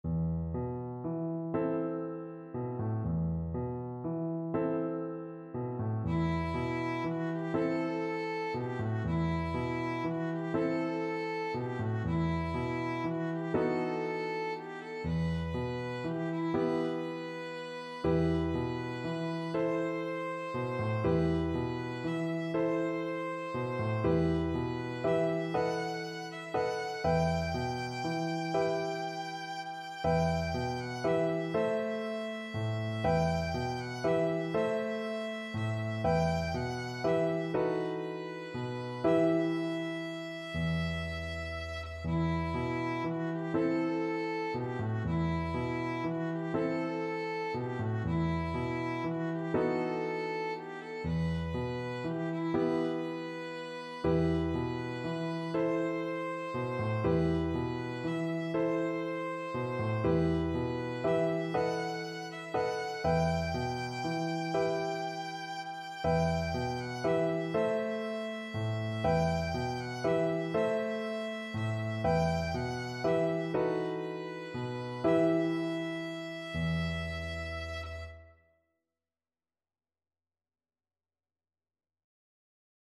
Violin
E minor (Sounding Pitch) (View more E minor Music for Violin )
Gently rocking .=c.40
6/8 (View more 6/8 Music)
Traditional (View more Traditional Violin Music)